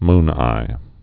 (mnī)